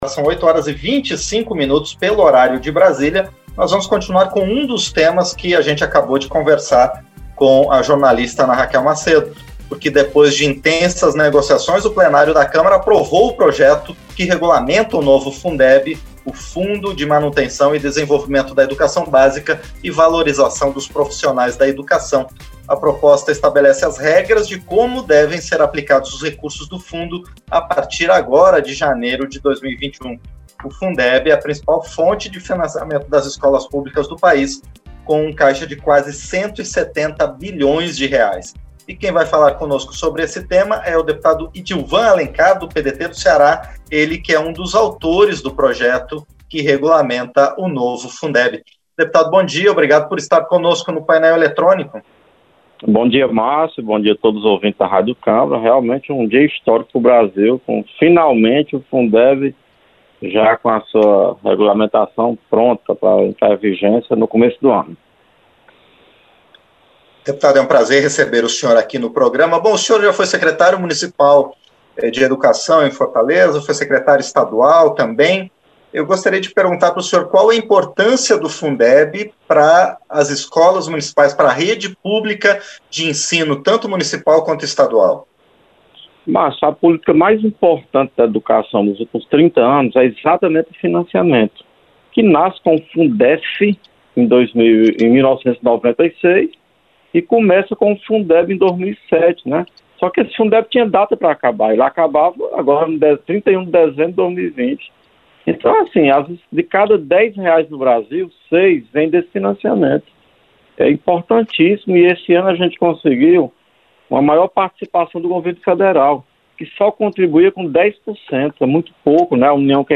Entrevista - Dep. Idilvan Alencar (PDT-CE)